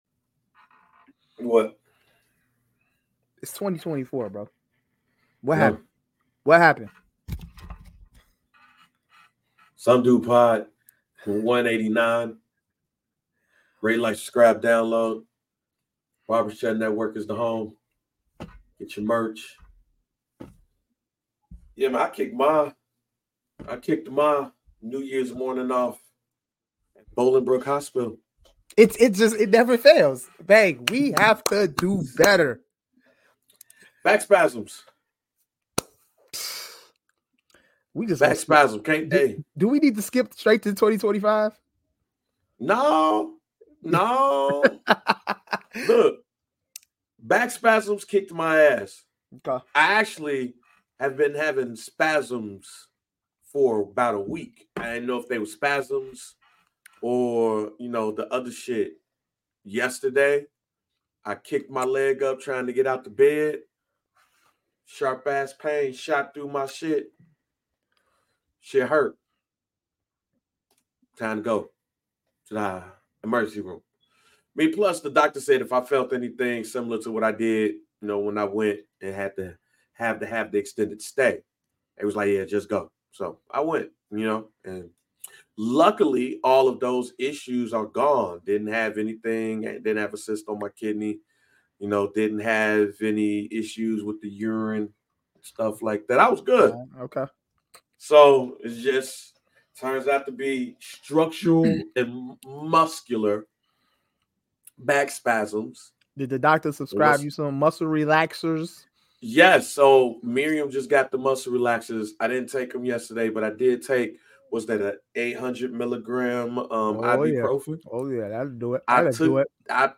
The fellas have a real in-depth conversation about health, "playing through pain," and therapy for the new year. They give their New Year's resolutions plus what they would like to see from their favorite teams, Justin Fields and the Dallas Cowboys, respectfully.